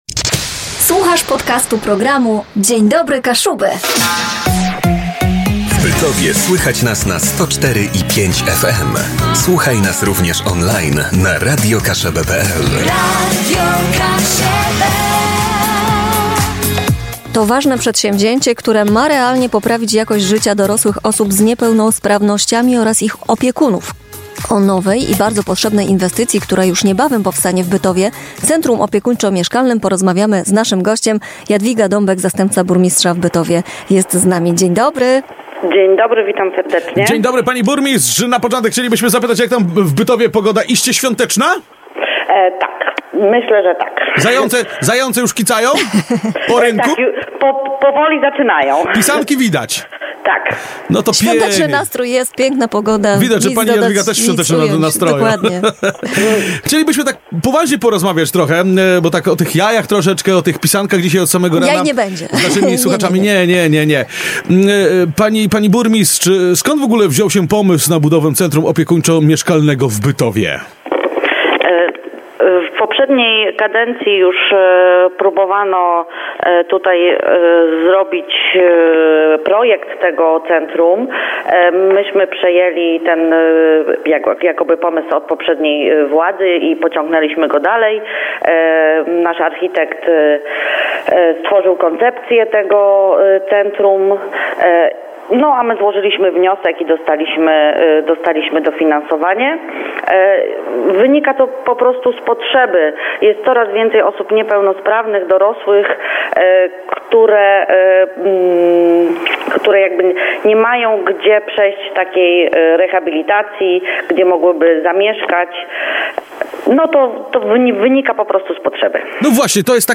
DDK-ROZMOWA-Jadwiga-Dabek-zastepca-burmistrza-w-Bytowie.mp3